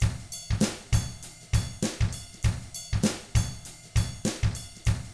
99FUNKY4T2-R.wav